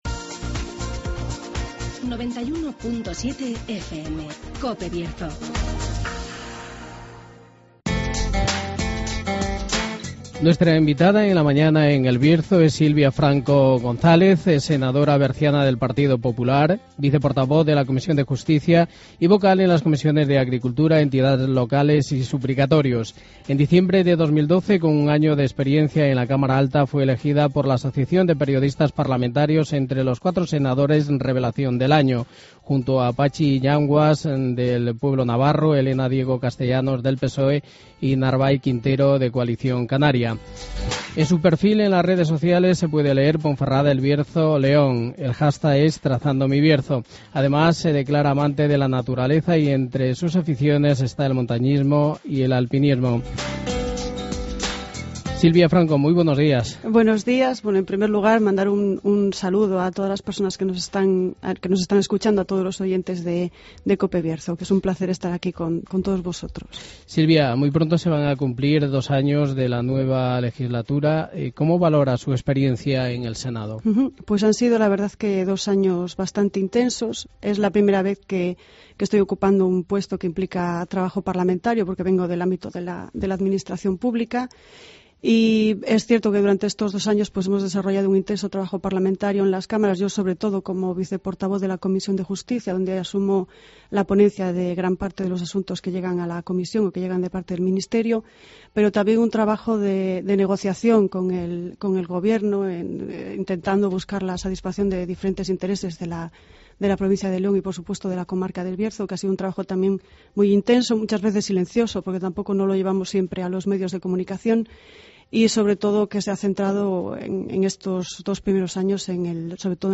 Entrevista a Silvia Franco González
AUDIO: Silvia Franco González, senadora berciana del PP.